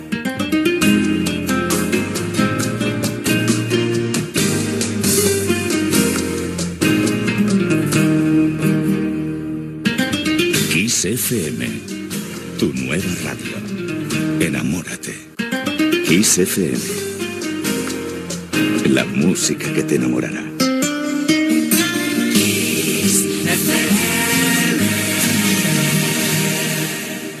Indicatiu de l'emissora "tu nueva radio"